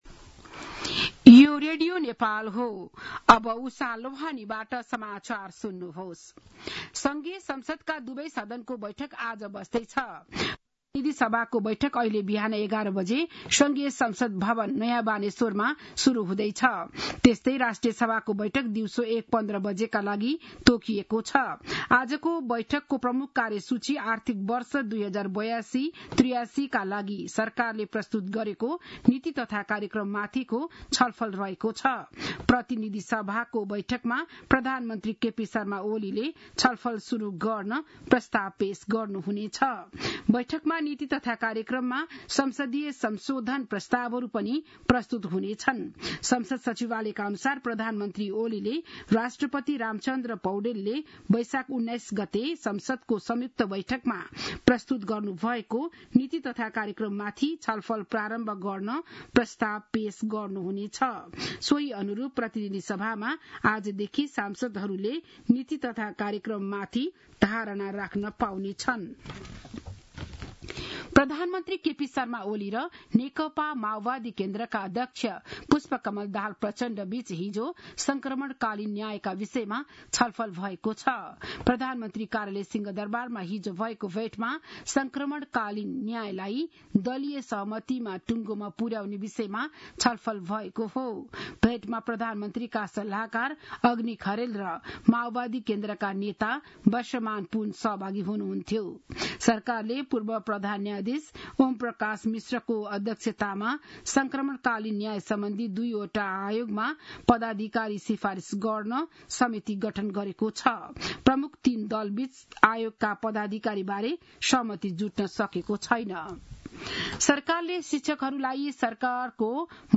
An online outlet of Nepal's national radio broadcaster
बिहान ११ बजेको नेपाली समाचार : २२ वैशाख , २०८२
11-am-Nepali-News-1-22.mp3